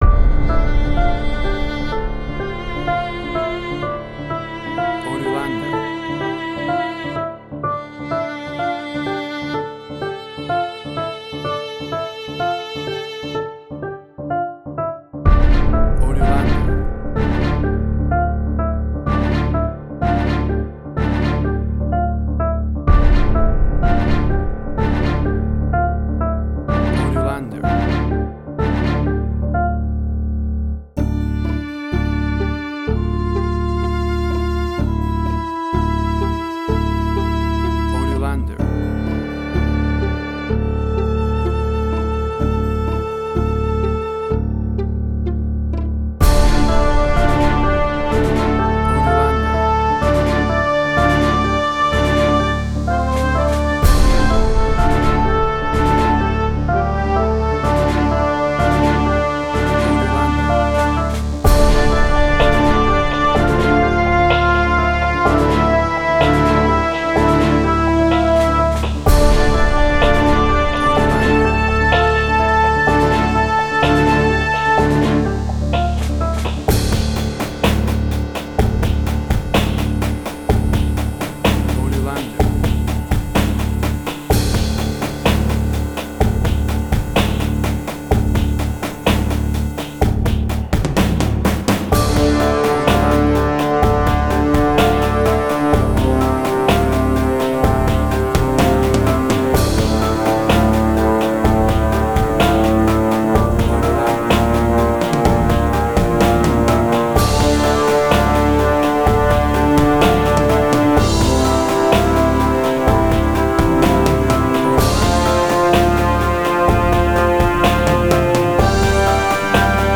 Suspense, Drama, Quirky, Emotional.
Tempo (BPM): 130